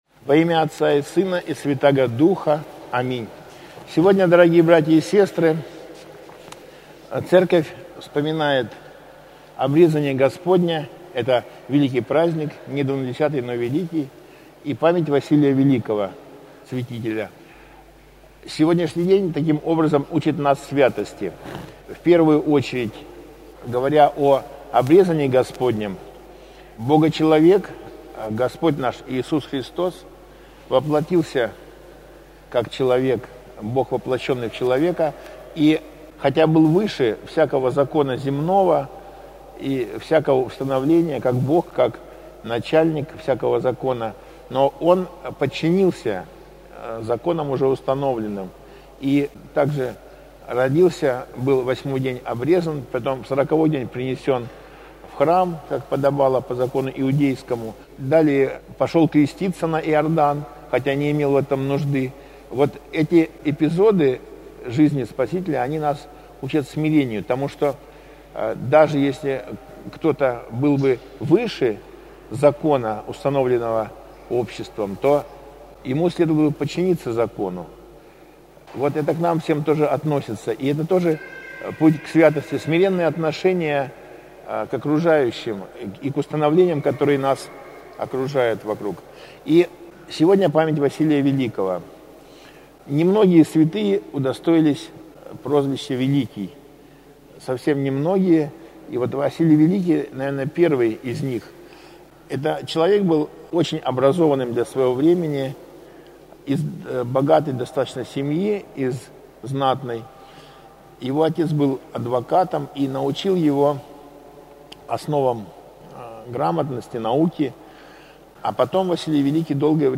Проповедь на Обрезание Господне - Кафедральный собор Христа Спасителя г. Калининграда